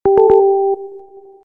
Anyone know where I might find the old "doodle-oo" tone from the Habbo Console, so I can set it as my ringtone?